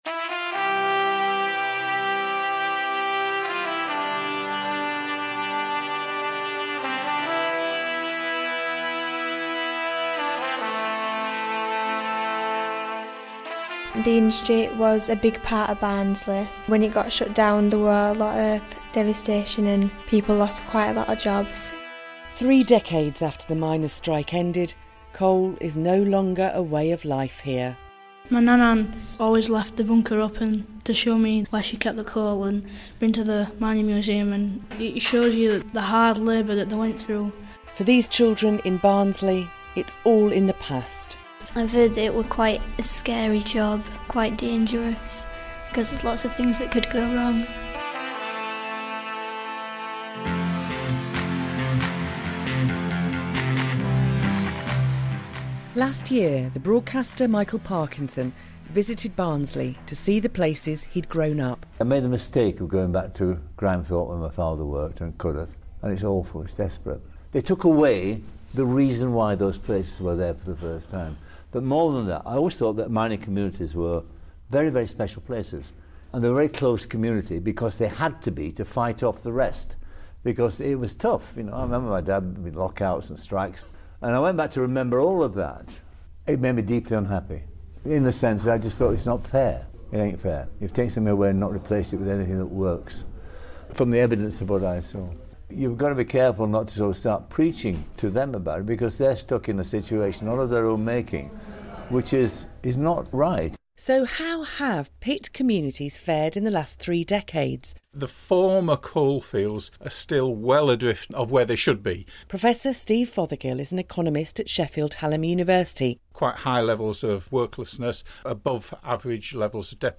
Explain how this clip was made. BBC Radio Sheffield has broadcast a documentary entitled We Came from Coal, speaking to people whose childhoods and teenage years were were shaped by the closing of the pits.